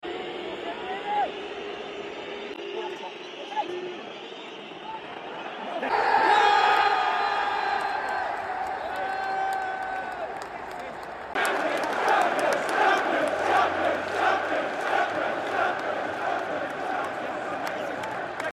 CHAMPIONS echoing round Anfield post